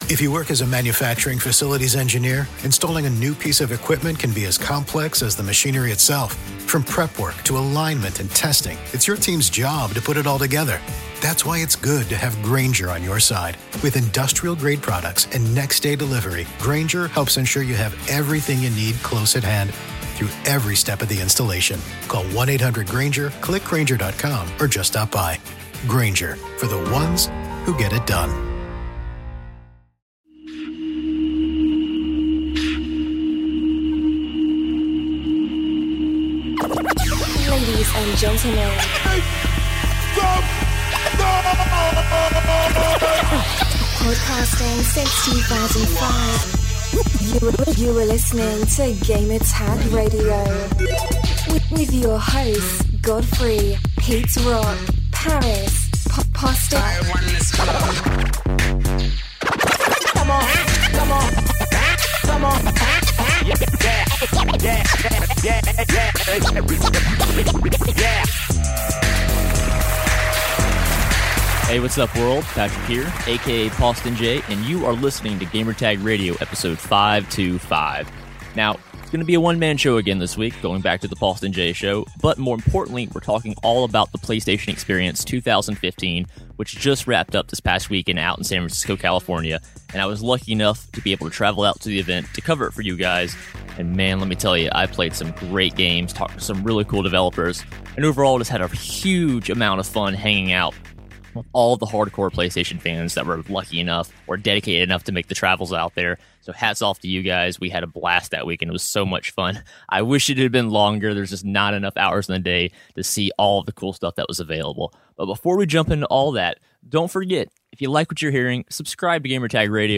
Interviews recorded during Playstation Experience 2015